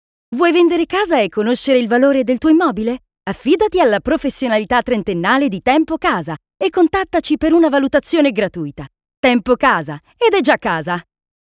Female
Corporate, Friendly, Smooth, Warm
My voice is sincere, soft, warm,versatile, friendly, natural, smooth.
Corporate.mp3
Microphone: Neumann Tlm 103